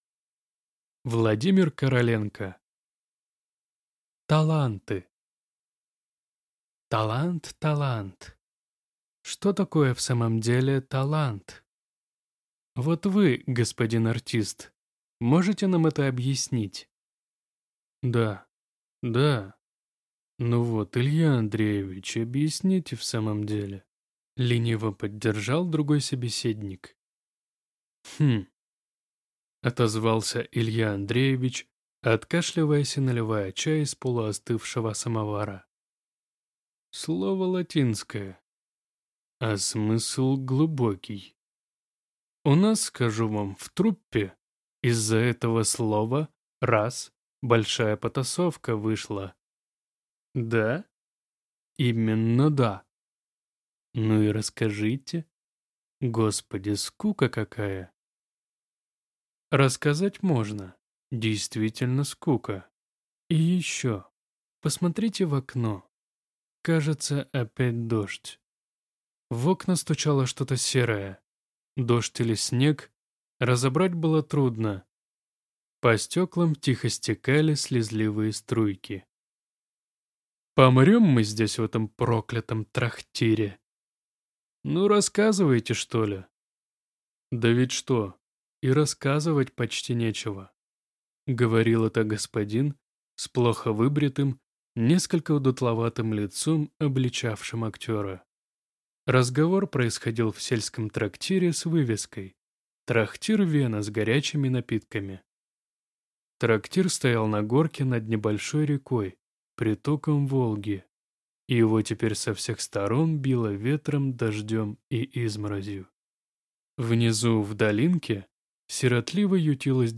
Аудиокнига Таланты | Библиотека аудиокниг